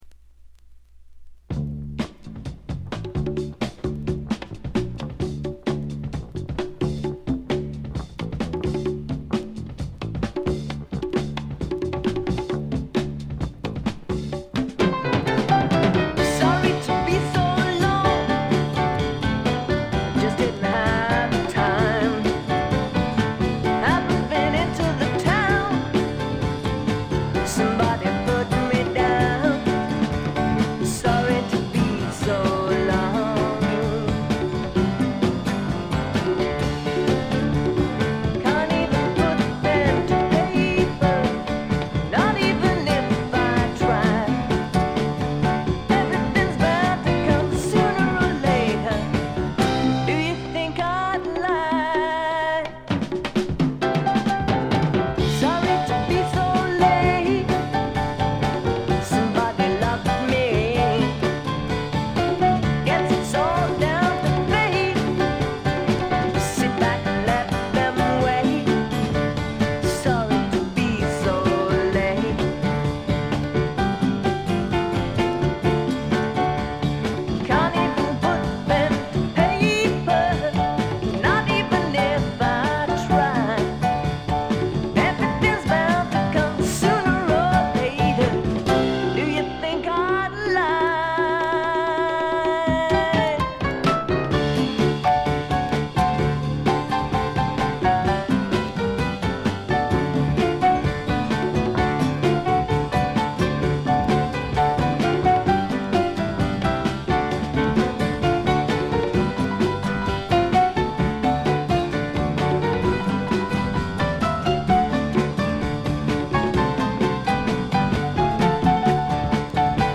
ホーム > レコード：英国 SSW / フォークロック
B2フェードアウトの消え際から無音部に周回ノイズ。
試聴曲は現品からの取り込み音源です。